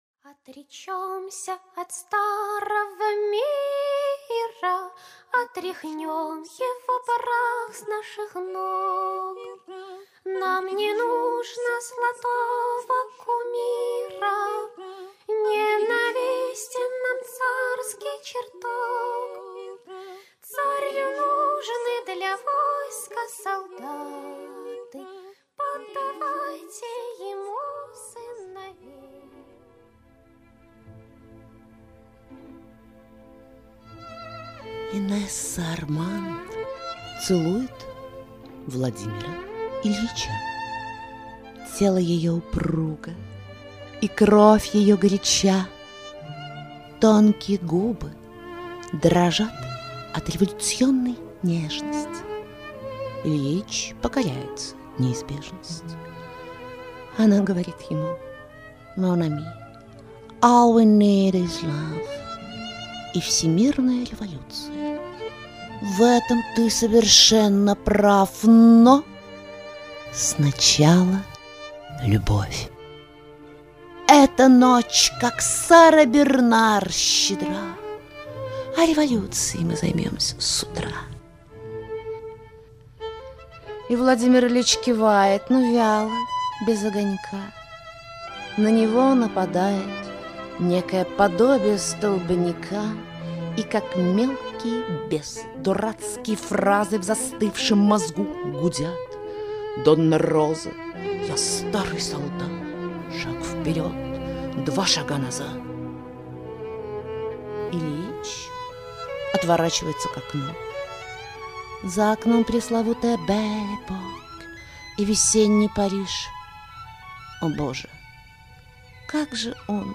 Музыкально-поэтические эксперименты